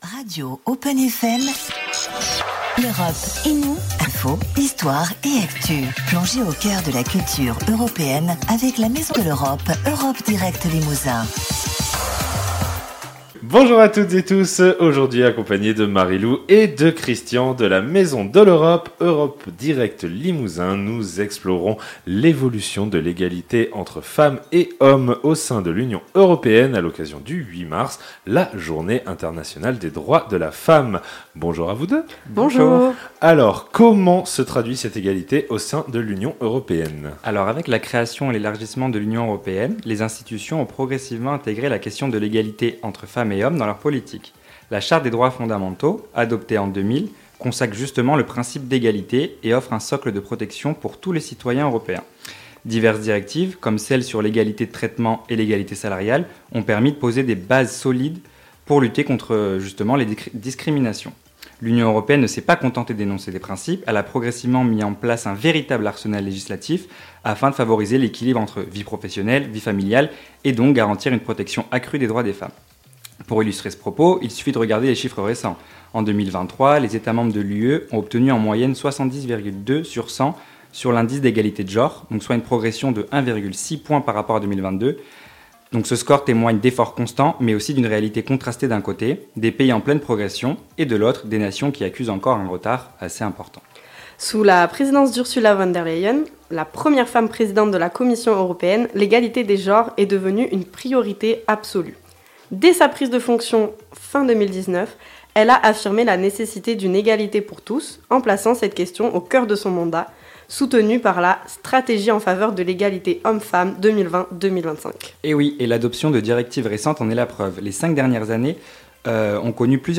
droits des femmes , égalité , journée internationale , radio